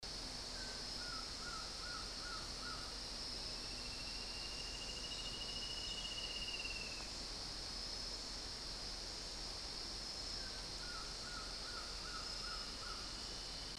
Surucuá Amarillo (Trogon chrysochloros)
Nombre en inglés: Atlantic Black-throated Trogon
Fase de la vida: Adulto
Localidad o área protegida: Refugio Privado de Vida Silvestre Yacutinga
Condición: Silvestre
Certeza: Vocalización Grabada